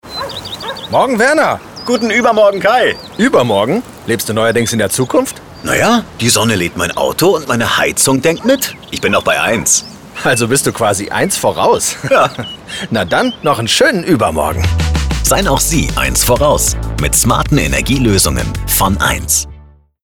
sehr variabel, dunkel, sonor, souverän, plakativ, markant
Mittel minus (25-45)
Funkspot | "eins Energie in Sachsen"
Commercial (Werbung)